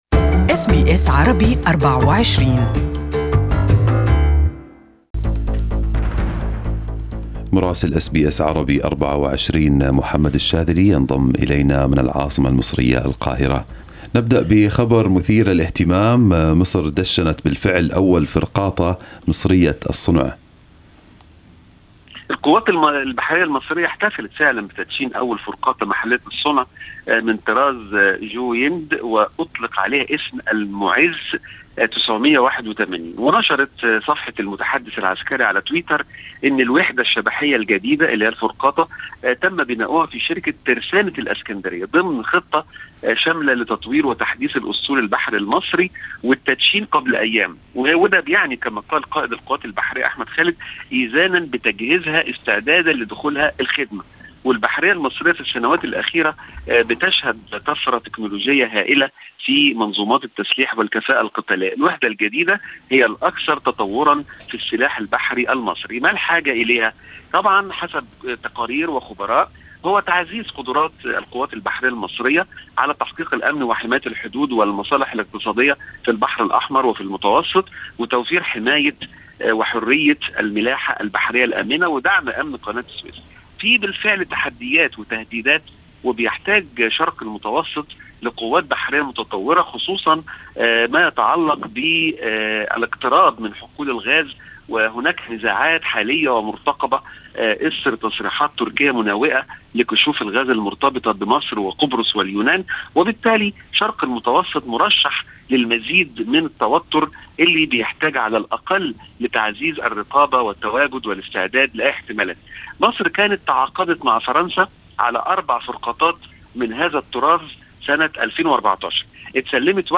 Our correspondent in Egypt has the details